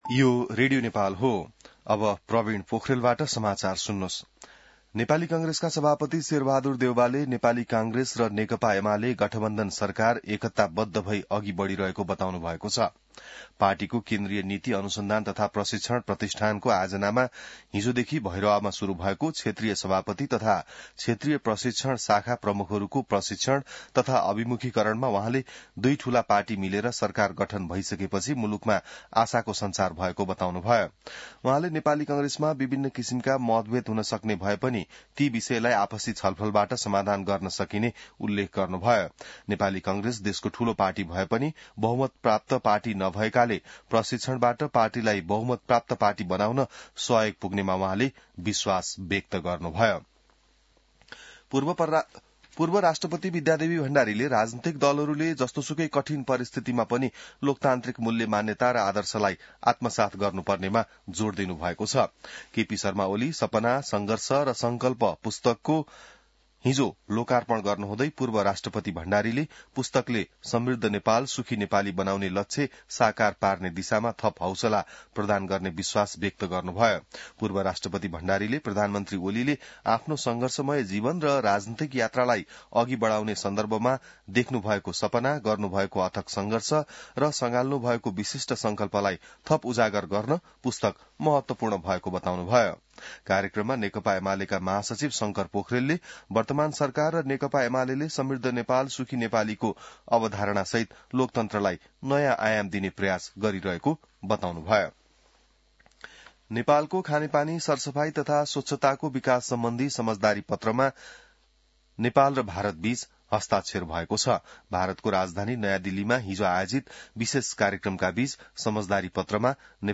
बिहान ६ बजेको नेपाली समाचार : २१ फागुन , २०८१